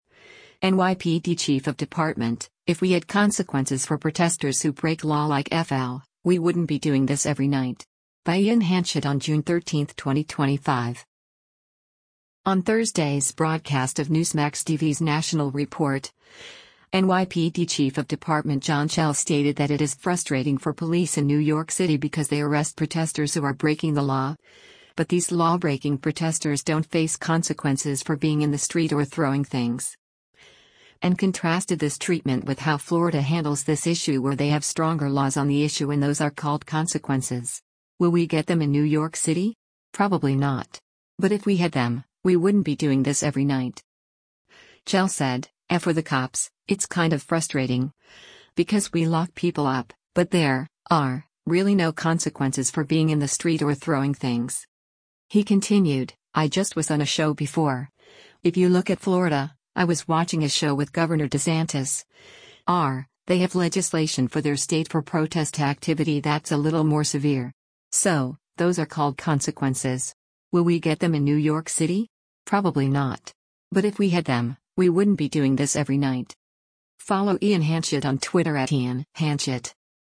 On Thursday’s broadcast of Newsmax TV’s “National Report,” NYPD Chief of Department John Chell stated that it is “frustrating” for police in New York City because they arrest protesters who are breaking the law, but these lawbreaking protesters don’t face consequences “for being in the street or throwing things.”